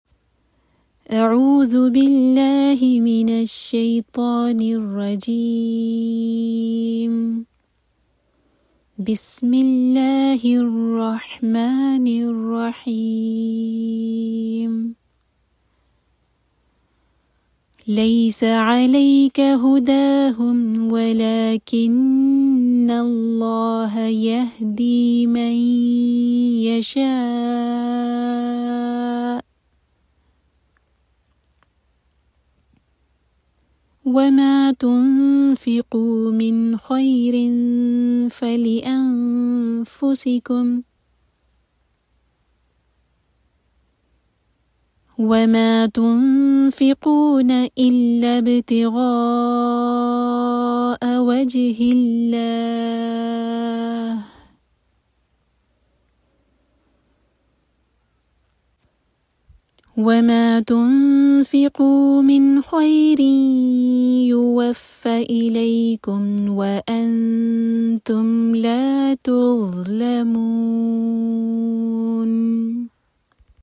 Tajweed Lessons
Practice